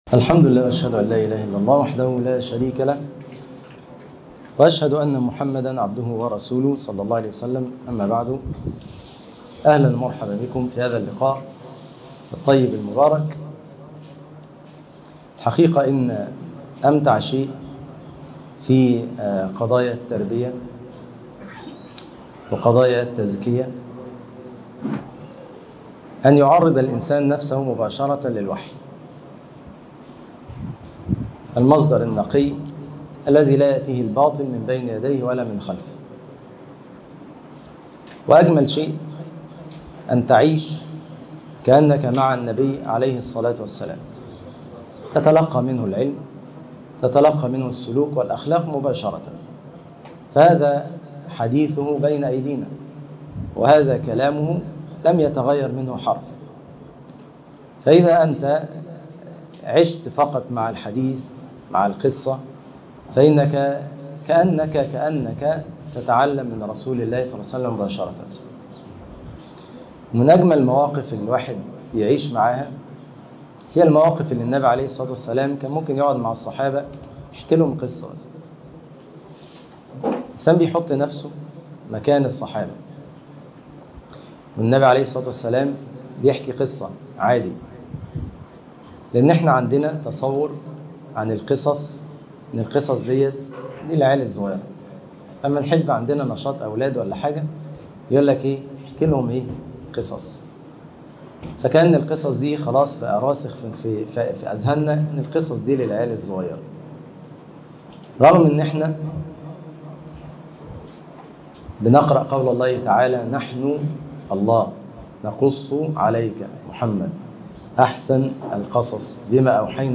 عجوز بنى اسرائيل - القصص النبوي - دورة علمني رسول الله - - درس جامد جدا - قسم المنوعات